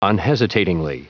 Prononciation du mot unhesitatingly en anglais (fichier audio)
unhesitatingly.wav